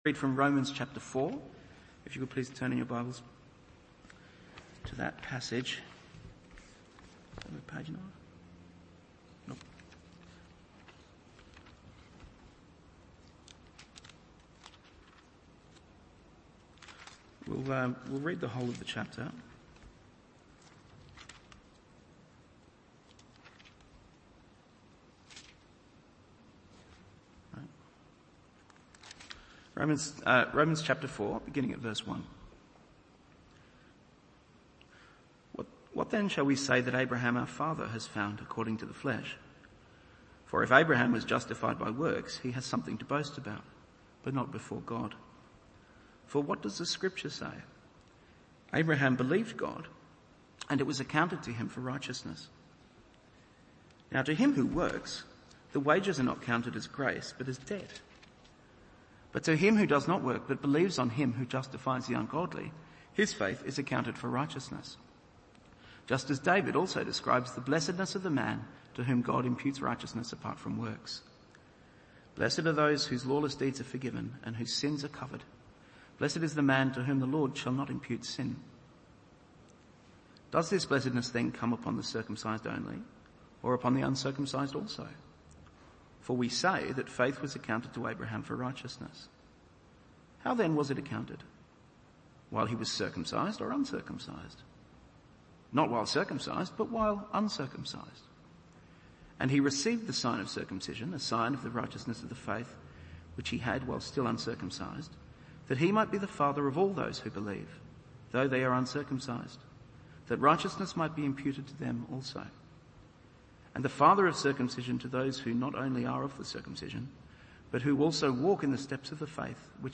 Evening Service Romans 4…